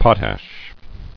[pot·ash]